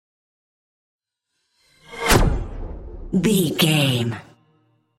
Pass by fast speed flash
Sound Effects
Fast paced
futuristic
pass by
sci fi
vehicle